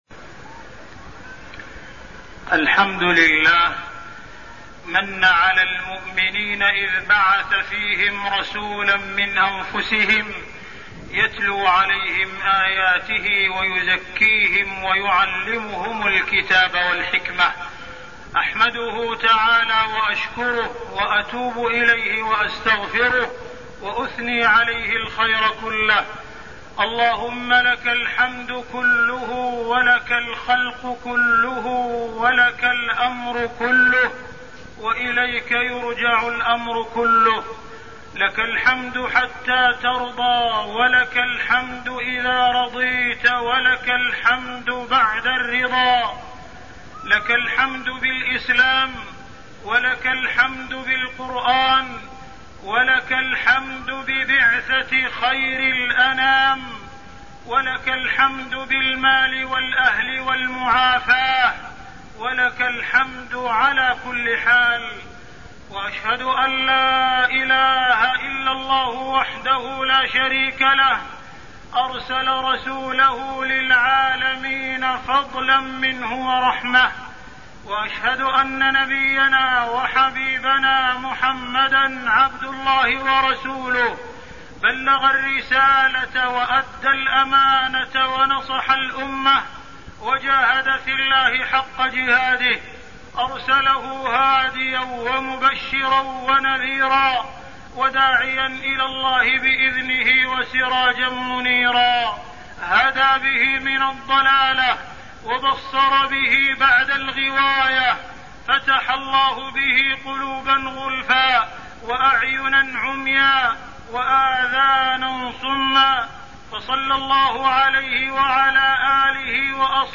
تاريخ النشر ٨ ربيع الأول ١٤١٦ هـ المكان: المسجد الحرام الشيخ: معالي الشيخ أ.د. عبدالرحمن بن عبدالعزيز السديس معالي الشيخ أ.د. عبدالرحمن بن عبدالعزيز السديس نشأة النبي صلى الله عليه وسلم